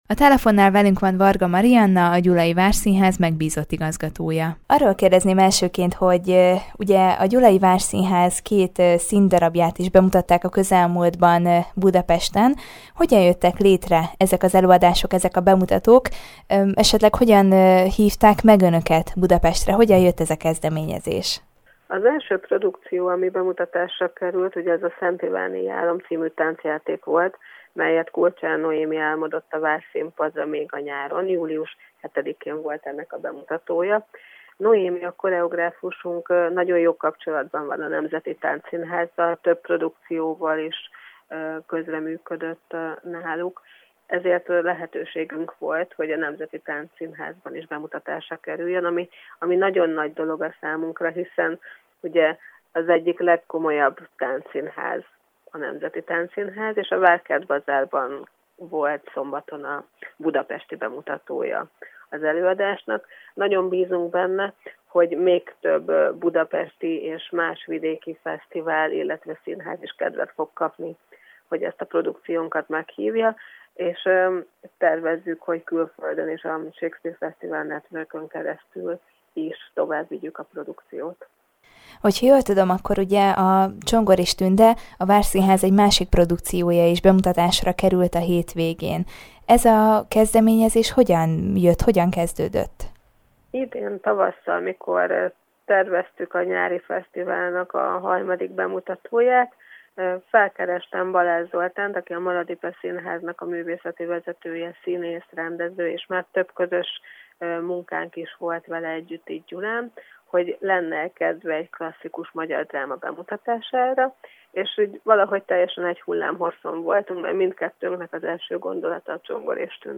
A Gyulai Várszínház két színdarabját is bemutatták a közelmúltban Budapesten. Ezzel kapcsolatban beszélgetett tudósítónk